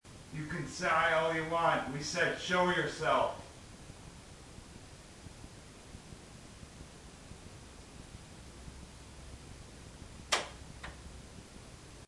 Loft – 2:16 am
Two knocks heard in response to question
loft-216-am-two-knocks.mp3